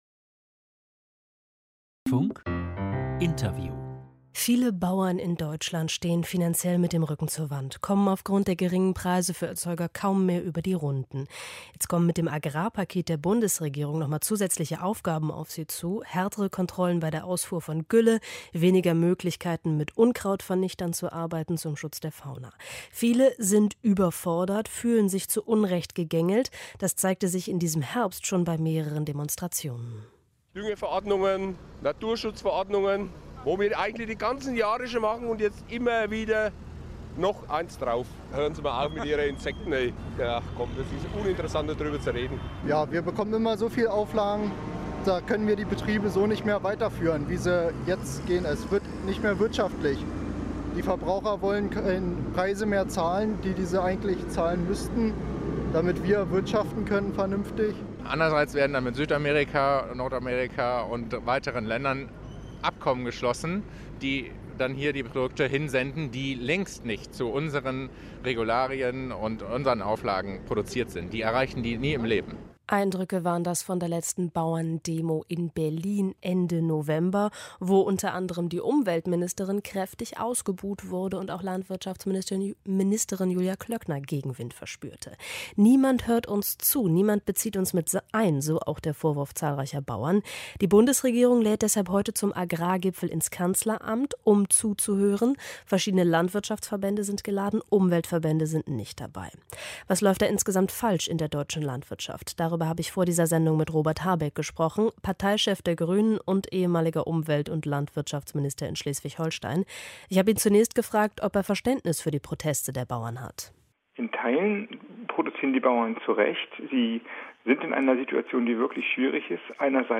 Hören Sie das Interview des Dlf mit Robert Habeck vom 2.12.2019: